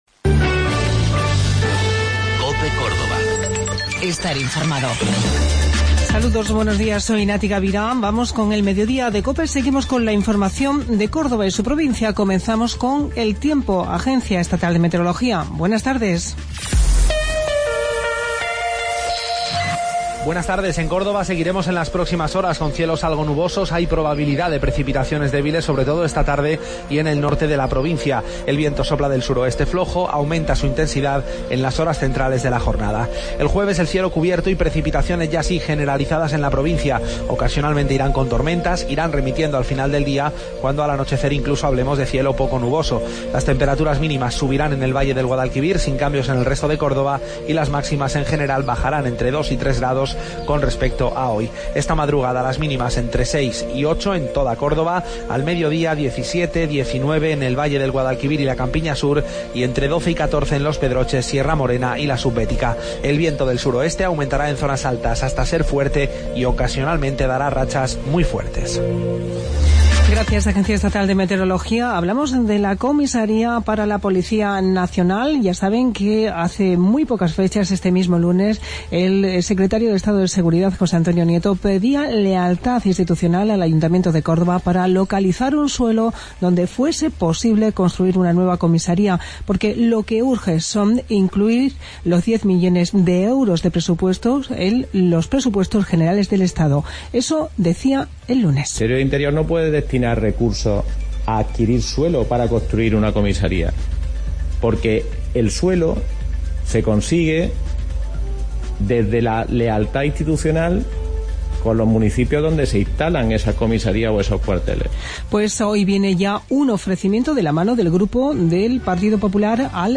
Mediodía en Cope. Informativo local 1 de Febrero 2017